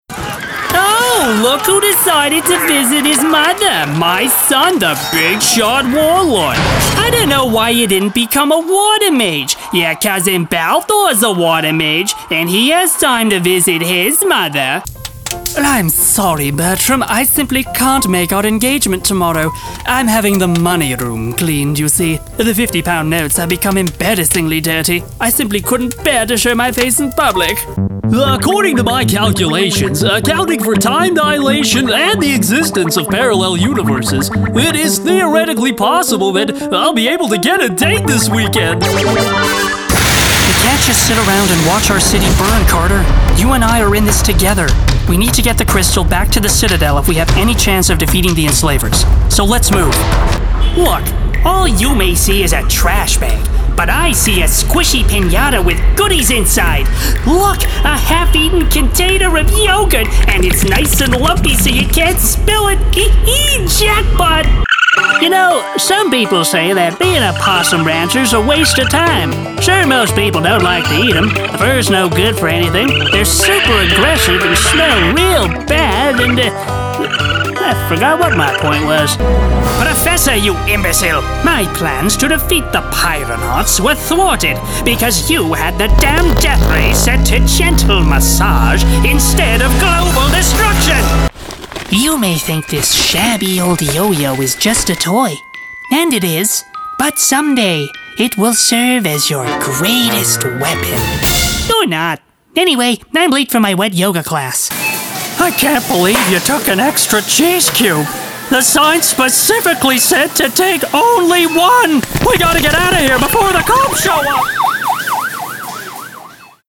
Playful. Dynamic. Confident. The Youthful Voice of Adventure!
Animation Demo
Teen
Young Adult
Cartoon / Animation
I work out of my home studio which is equipped with a CAD-E100s Condenser microphone, and SSL2 audio interface.